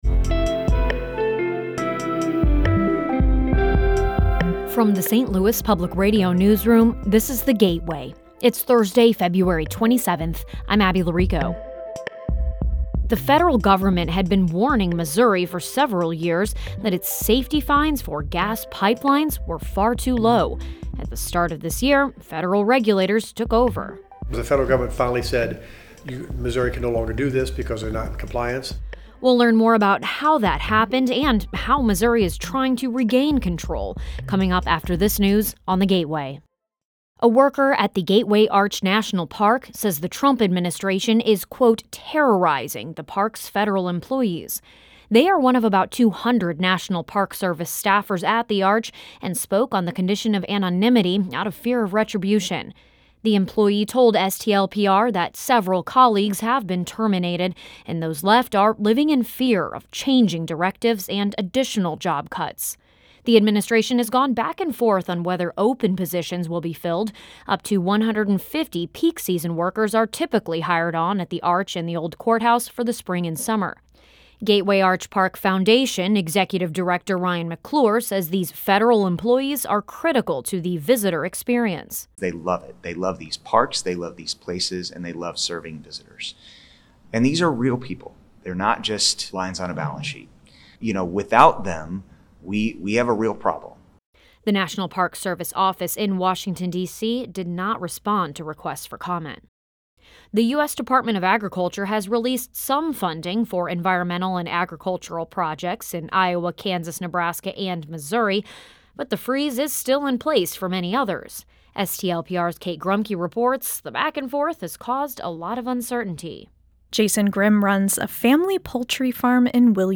Essential news for the St. Louis region. Every weekday, in about 8 to 10 minutes, you can learn about the top stories of the day, while also hearing longer stories that bring context and humanity to the issues and ideas that affect life in the region.